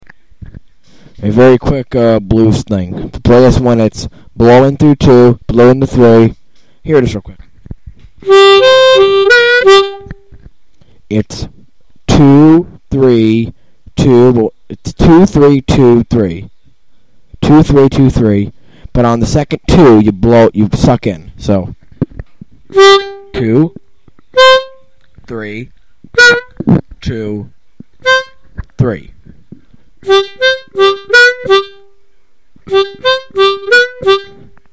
The Blues 2323 rift
Demo Clip